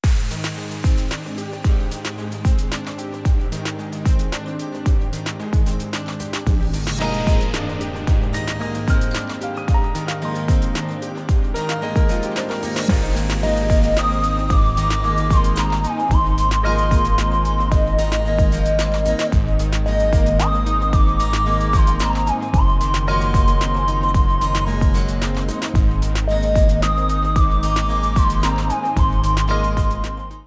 Trimmed, normalized and added fade-out.